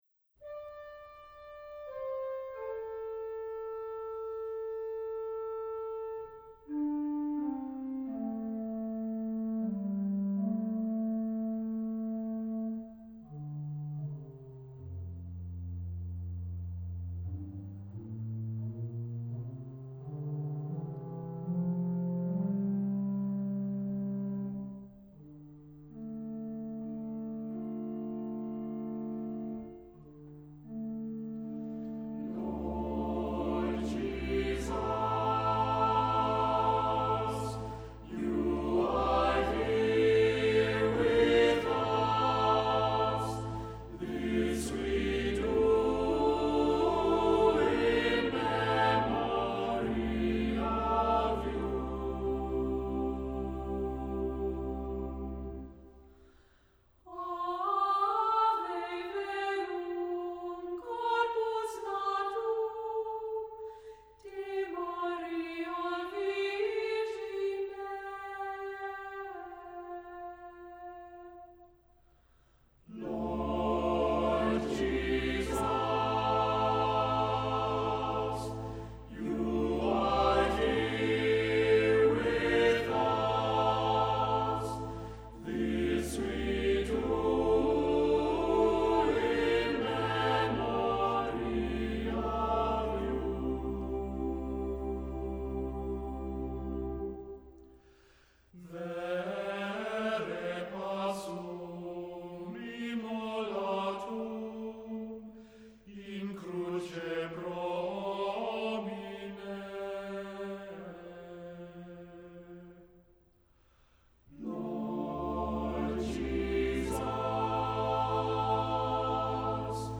Accompaniment:      Organ
Music Category:      Christian
Assembly part is optional.